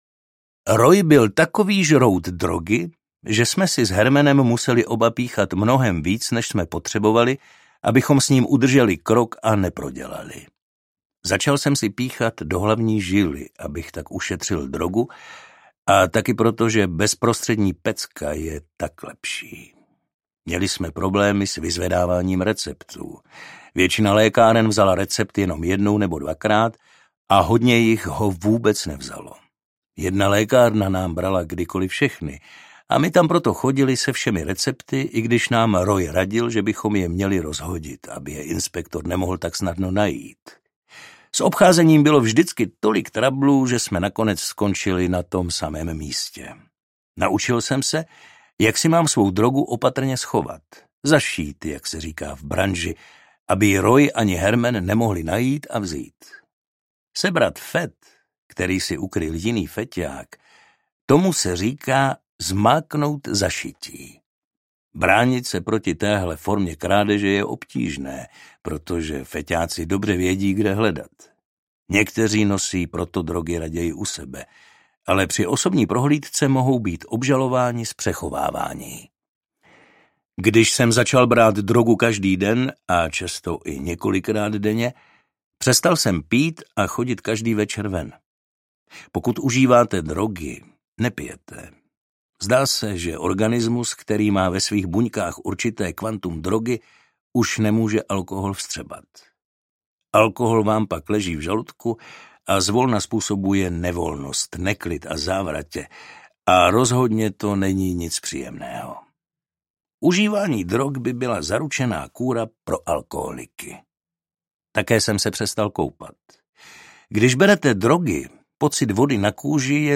Feťák audiokniha
Ukázka z knihy